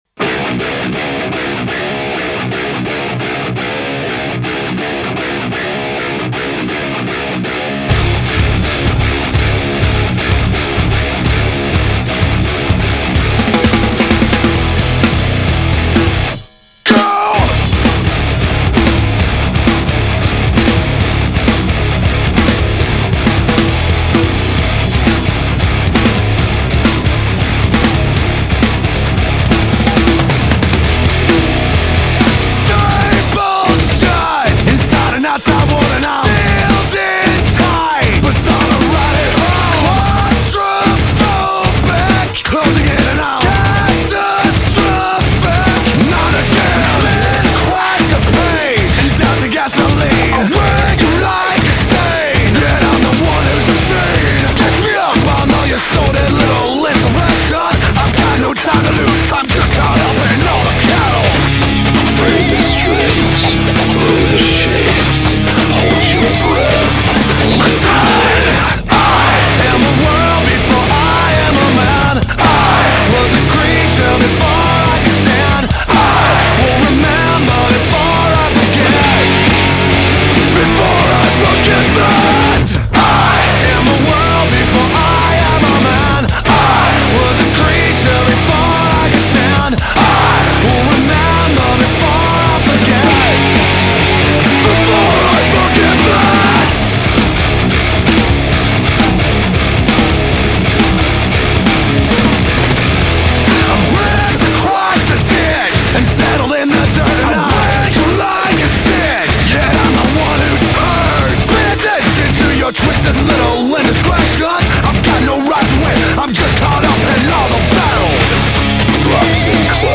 Категория: Рок, Альтернатива